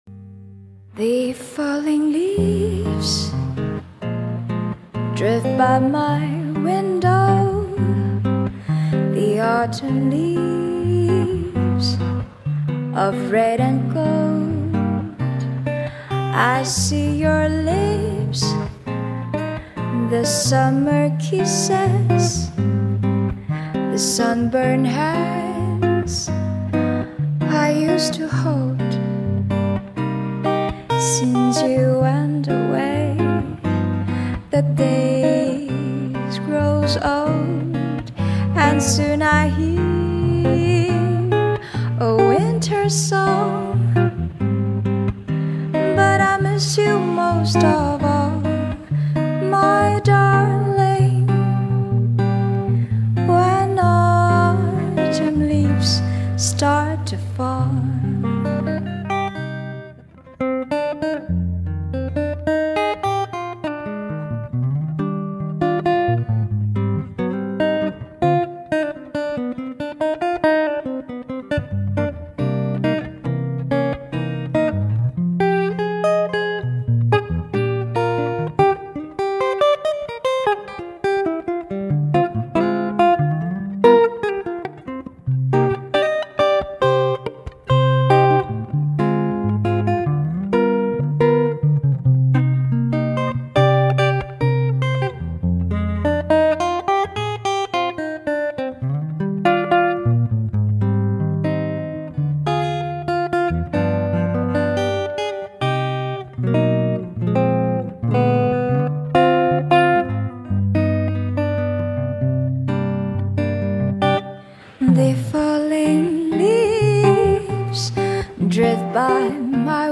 Cùng nghe đoạn soundtest nhanh về mẫu switch từ hmx. Switch thiên hướng clack nhẹ mượt mà âm lượng 8/10 xinhai và ít poppy hơn.